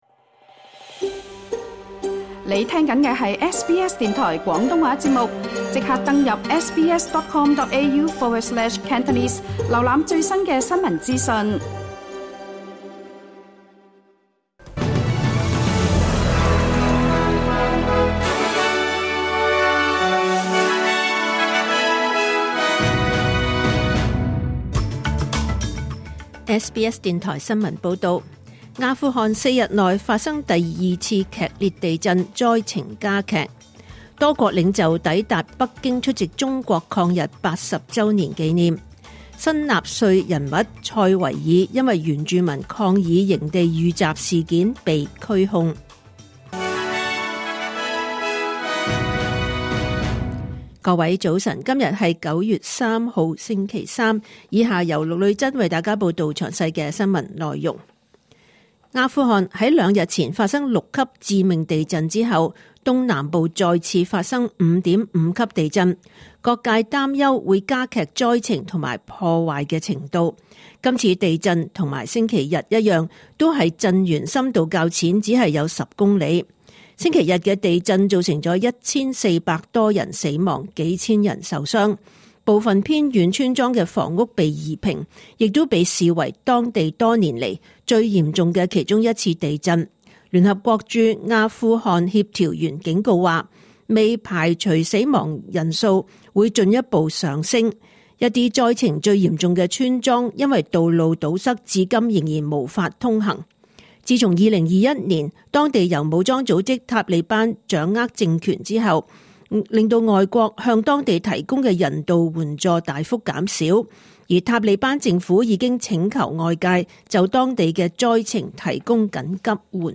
2025年9月3日 SBS 廣東話節目九點半新聞報道。